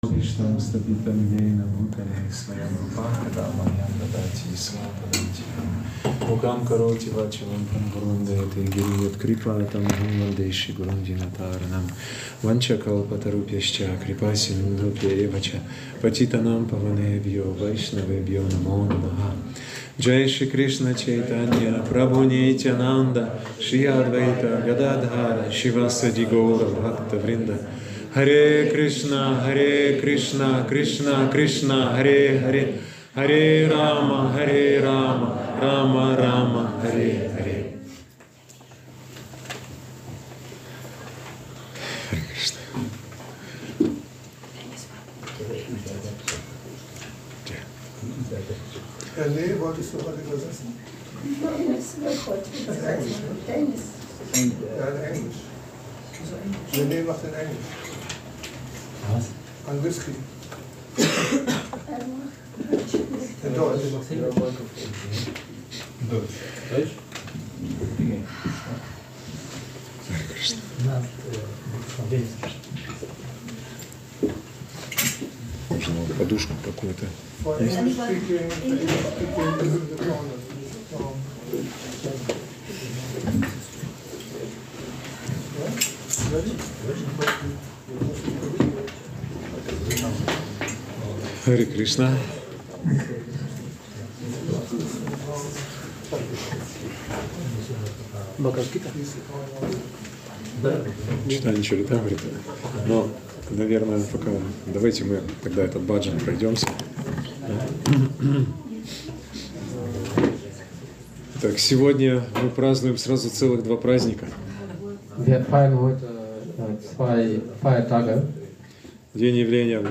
Der erste große Feiertag des krishnabewussten Jahres stand für den 10. Februar 2025 auf dem Kalender und wurde am Sonntag, den 9. Februar in unserem Tempel gefeiert.